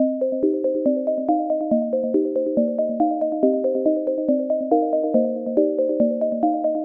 凉爽或Dubstep Arp Synth 140 BPM
标签： 140 bpm Chill Out Loops Synth Loops 1.15 MB wav Key : Unknown
声道立体声